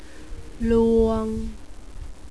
one pronounce for    " ua " two pronounces             " ua "   " uer "